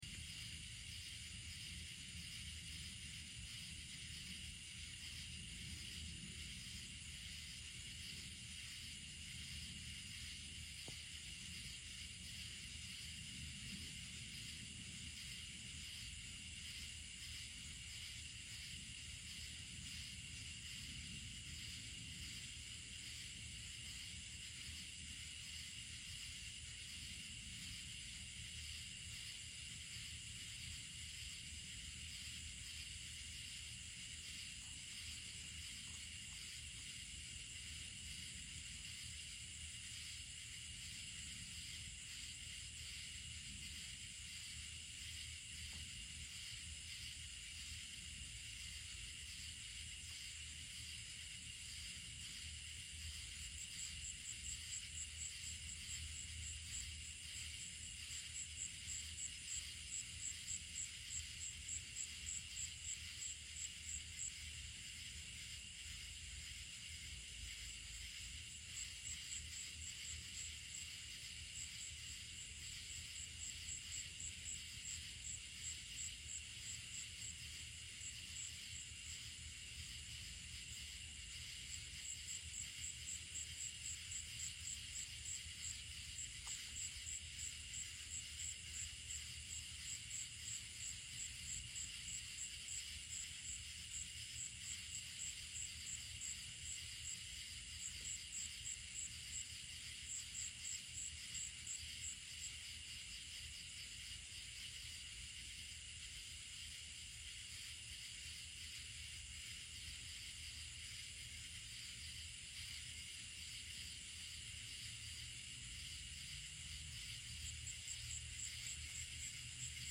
Where you can hear the frogs and the crickets battle it out. You may be able to sit and relax.